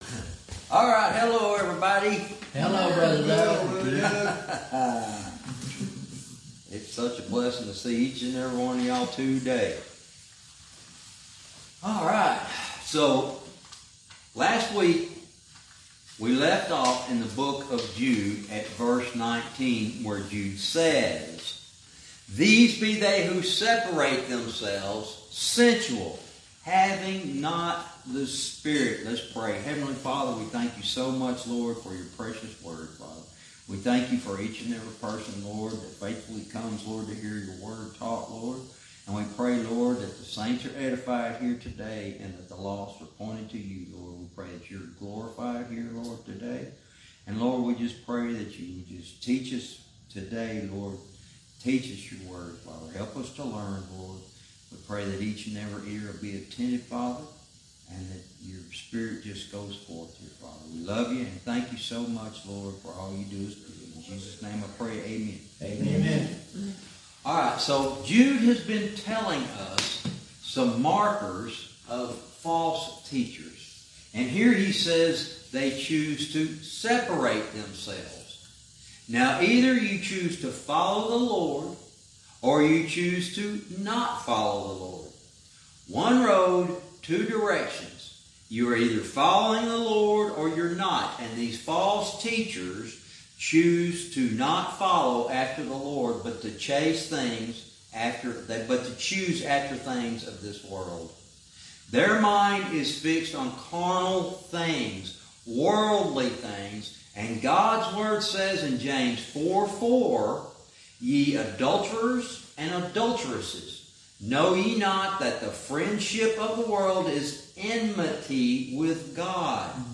Verse by verse teaching - Jude lesson 85 verse 19